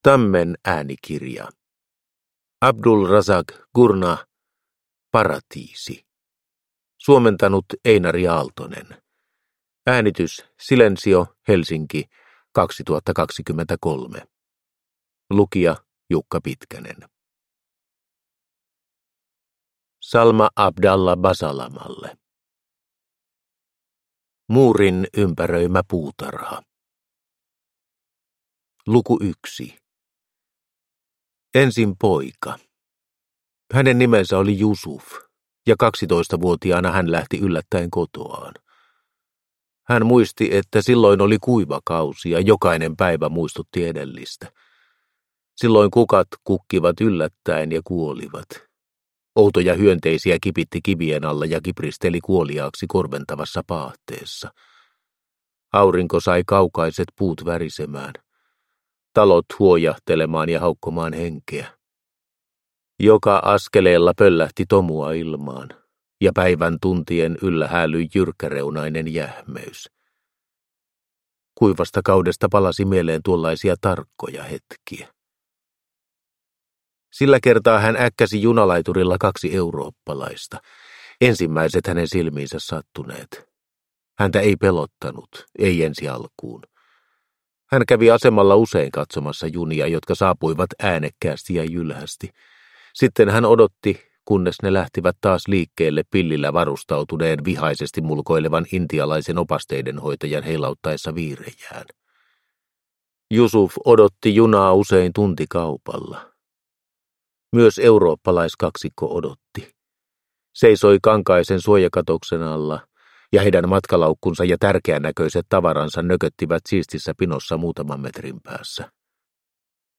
Paratiisi (ljudbok) av Abdulrazak Gurnah